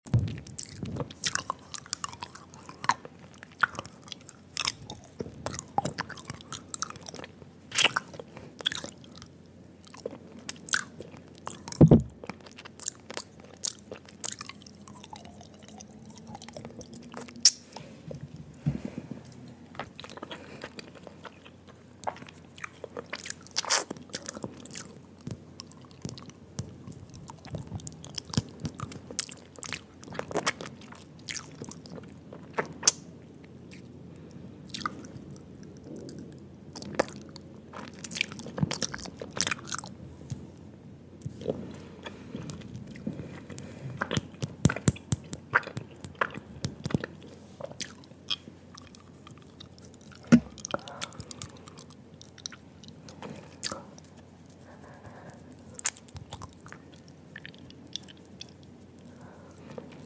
Asmr | Spit Painting 👄 Sound Effects Free Download